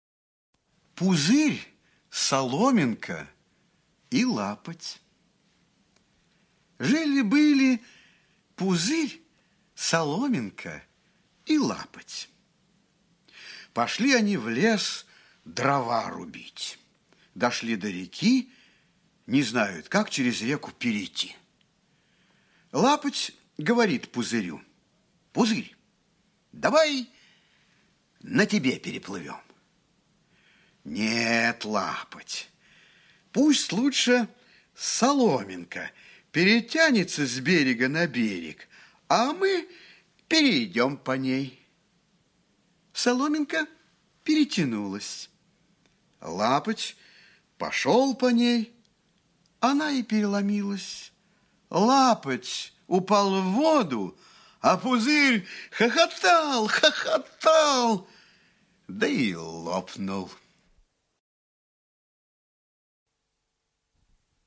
1. «Аудио сказка малышам – Пузырь, Соломинка и Лапоть» /
skazka-malysham-Puzyr-Solominka-i-Lapot-stih-club-ru.mp3